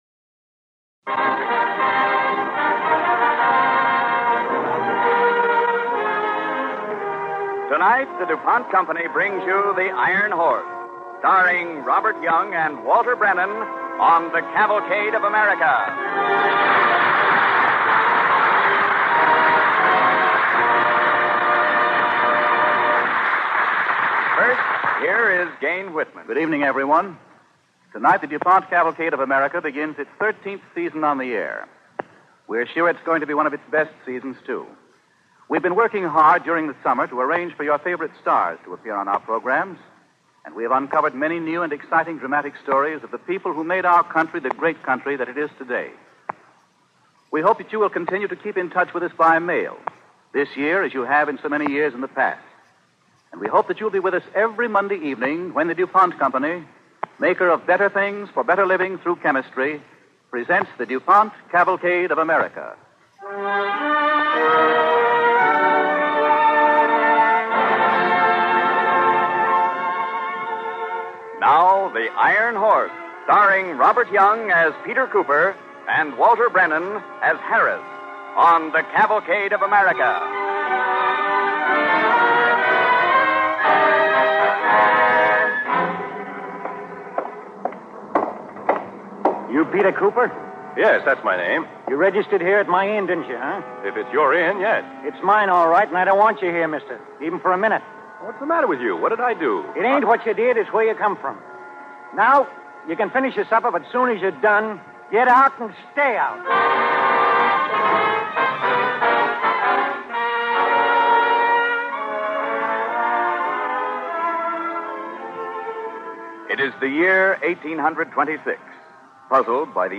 Cavalcade of America Radio Program
The Iron Horse, starring Robert Young and Walter Brennan